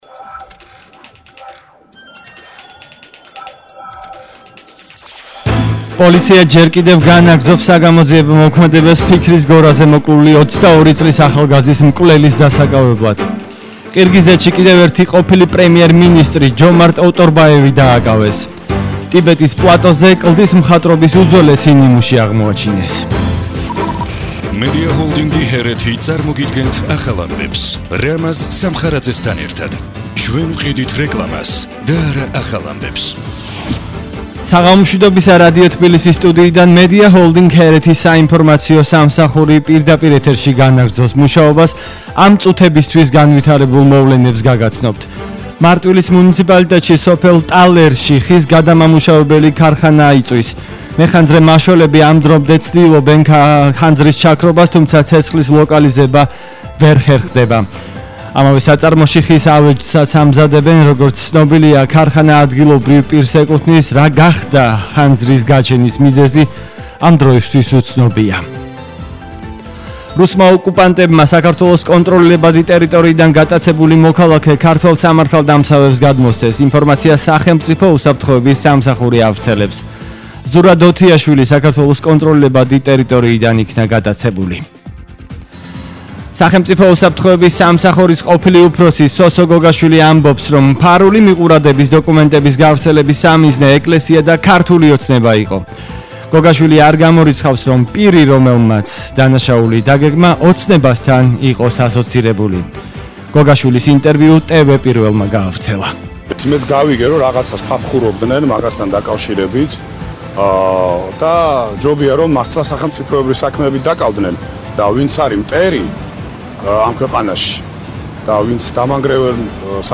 ახალი ამბები 20:00 საათზე –16/09/21